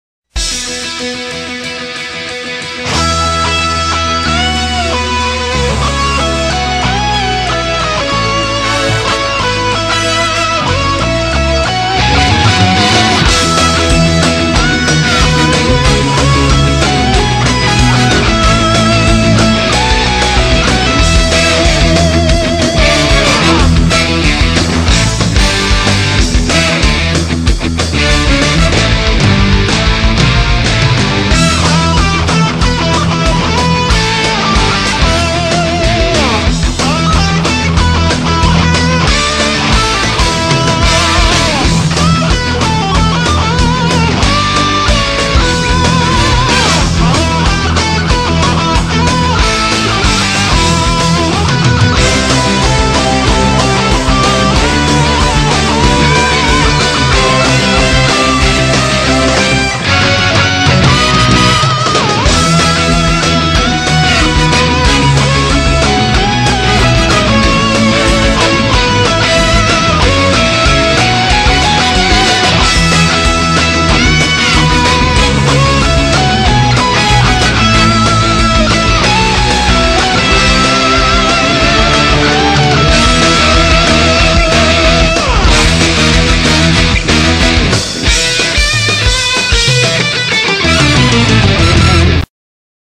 BPM186
Audio QualityCut From Video